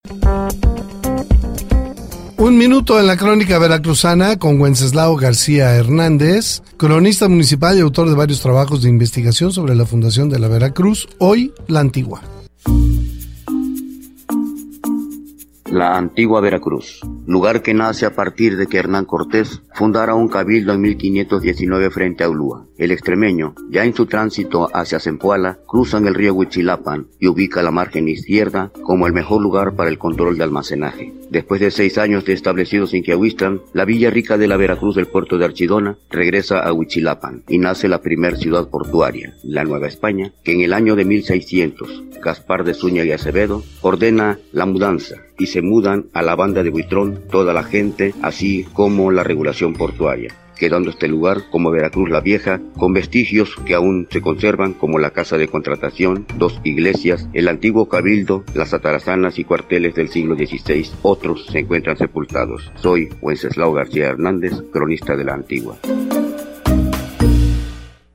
Aquí les compartimos las grabaciones sacadas al aire de la sección del noticiero referido.